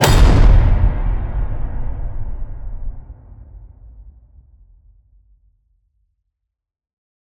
player_hit.wav